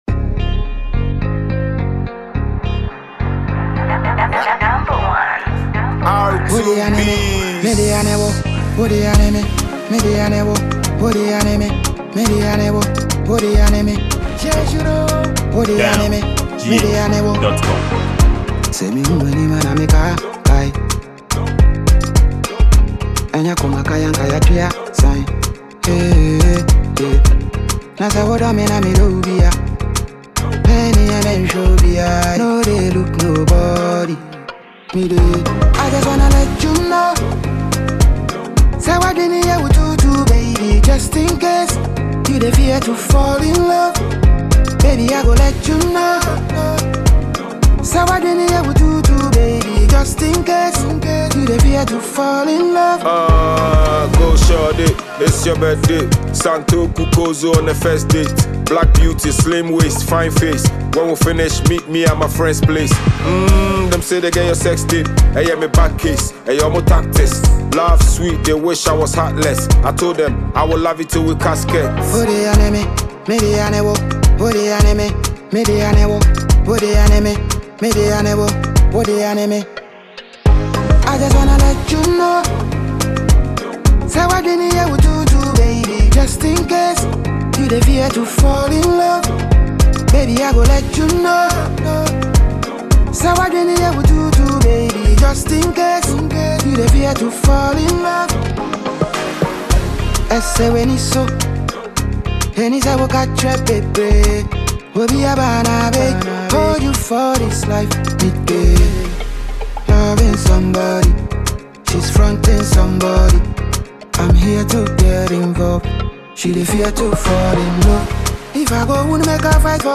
Ghanaian top-rated award winning music duo